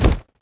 drop_pot.wav